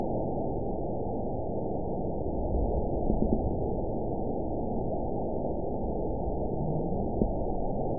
event 917280 date 03/26/23 time 15:25:49 GMT (2 years, 1 month ago) score 8.52 location TSS-AB04 detected by nrw target species NRW annotations +NRW Spectrogram: Frequency (kHz) vs. Time (s) audio not available .wav